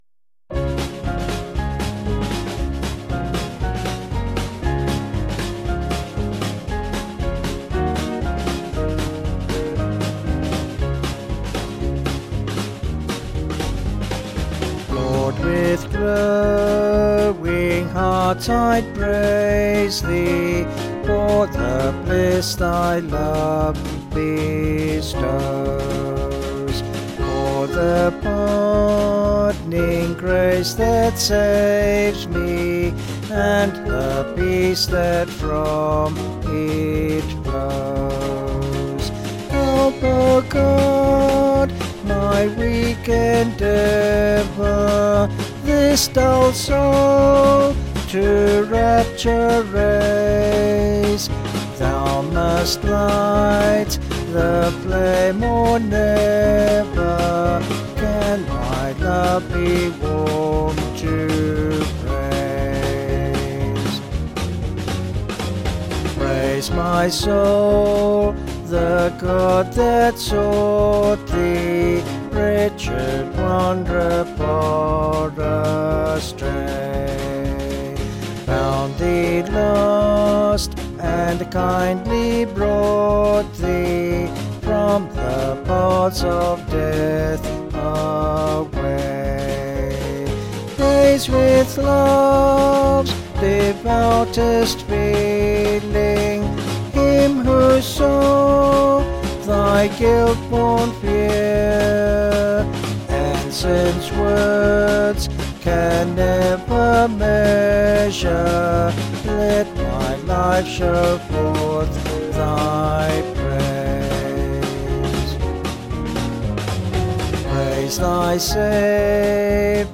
Vocals and Band   264kb Sung Lyrics